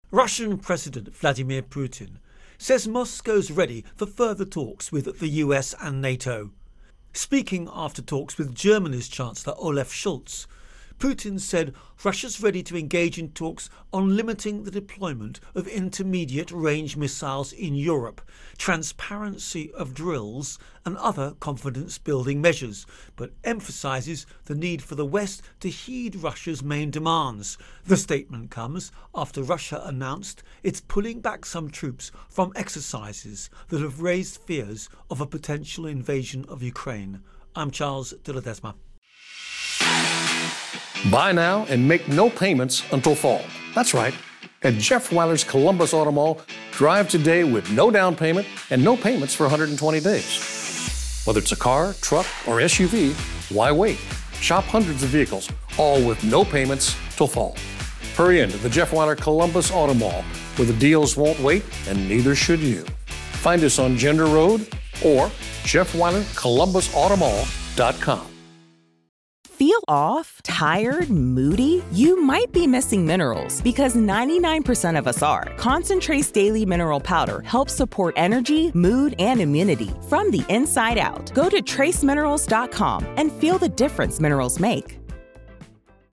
Ukraine-Tensions-Russia Intro and Voicer